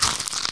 脆物入口-YS070521.wav
通用动作/01人物/02普通动作类/脆物入口-YS070521.wav
• 声道 單聲道 (1ch)